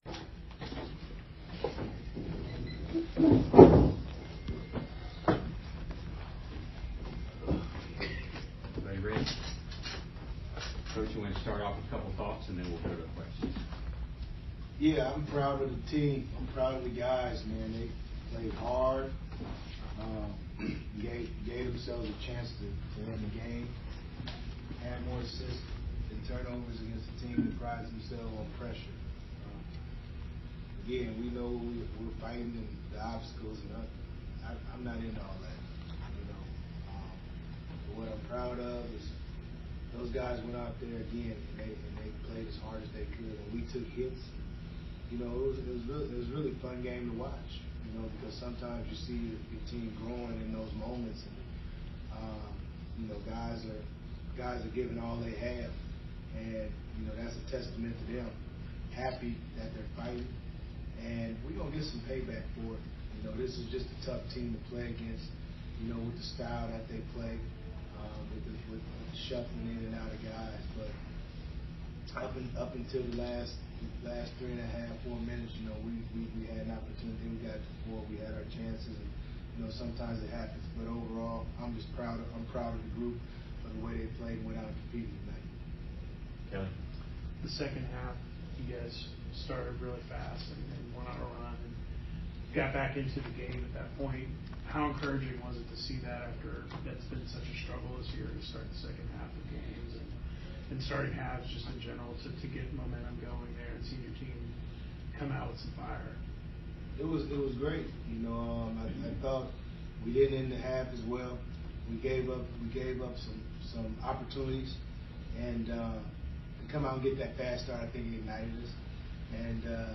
POST-GAME PRESS CONFERENCE AUDIO